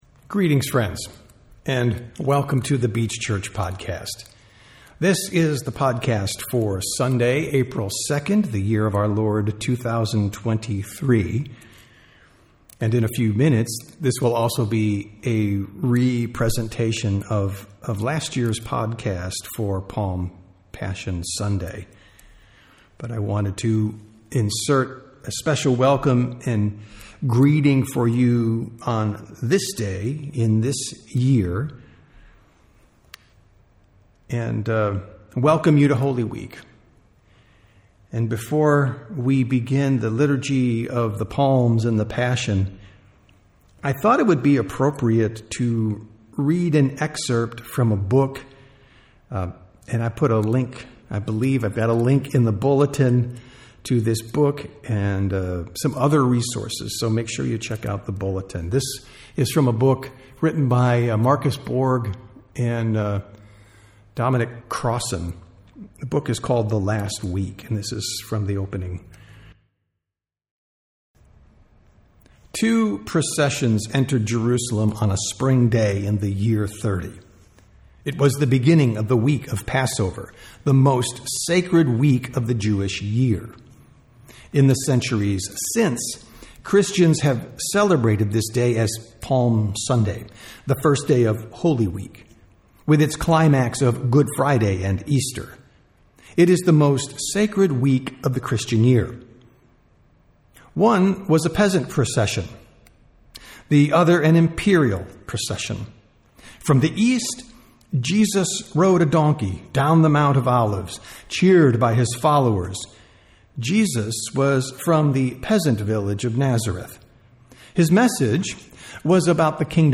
Sermons | The Beach Church
Sunday Worship - April 2, 2023